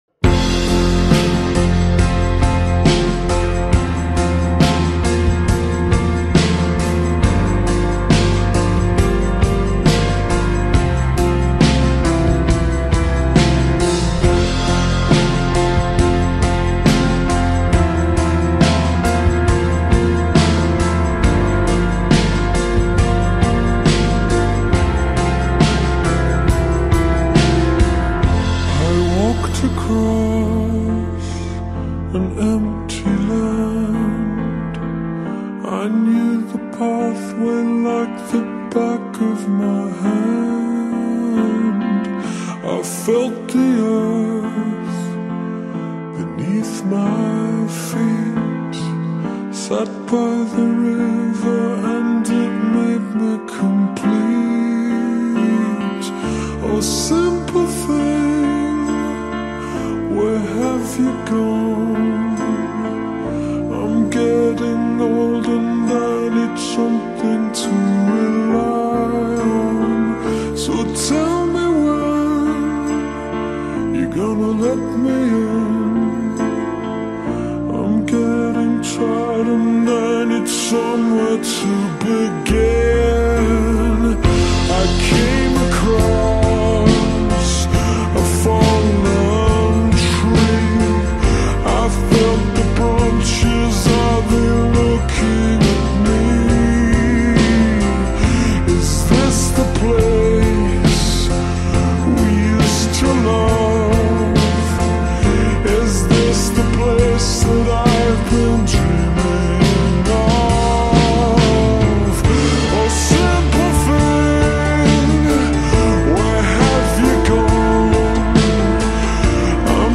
غمگین
غمگین خارجی